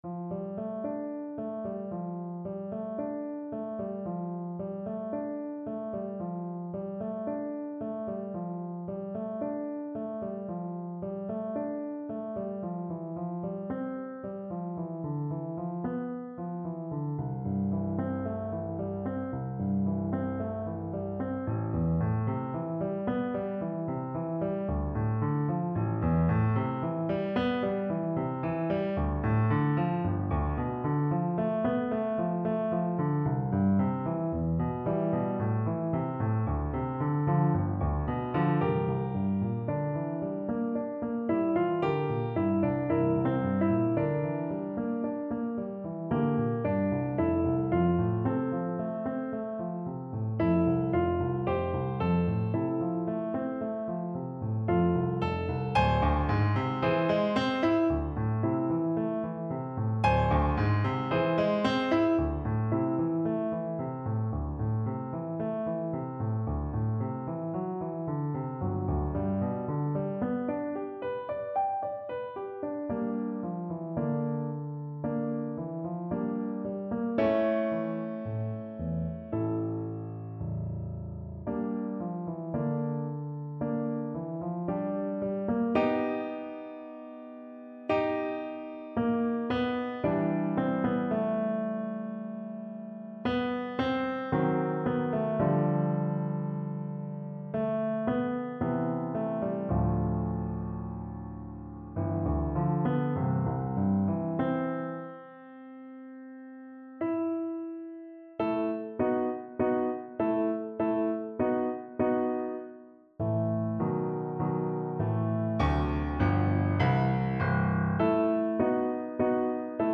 ClarinetPiano
4/4 (View more 4/4 Music)
Clarinet  (View more Intermediate Clarinet Music)
Classical (View more Classical Clarinet Music)
Nostalgic Music for Clarinet